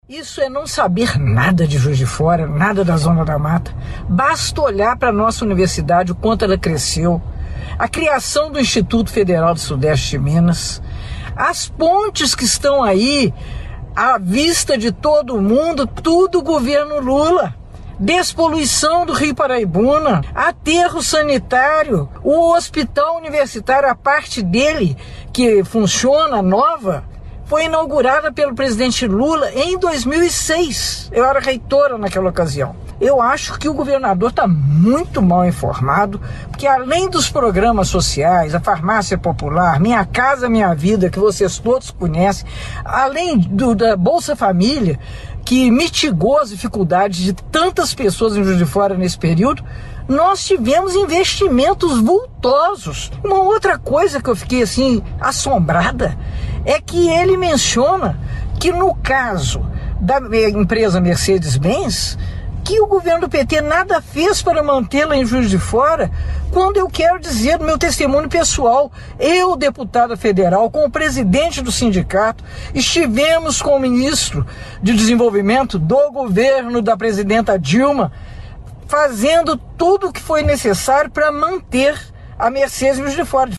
Com agenda em Belo Horizonte, a prefeita de Juiz de Fora, Margarida Salomão (PT) publicou um vídeo nas redes sociais rebatendo as falas do governador em entrevista a uma rádio da cidade.
margarida-rebate-fala-zema.mp3